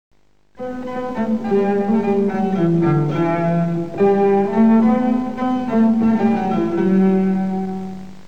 Sigla finale